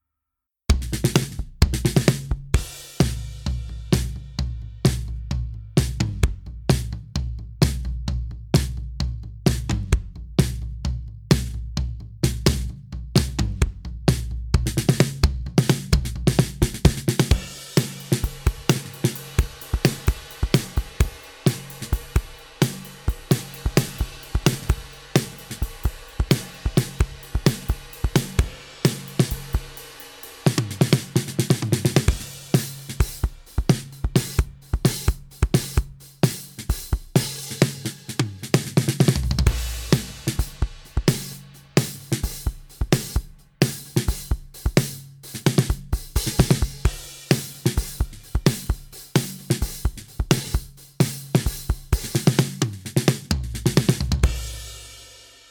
架子鼓 MixWave Underoath Aaron Gillespie KONTAKT-音频fun
MixWave: Underoath - Aaron Gillespie 提供了两种不同的鼓组合，一种是原声的，另一种是经过老式混音的，每种都包含了 2 个踢鼓、6 个军鼓、6 个鼓皮和 4 个铜钹。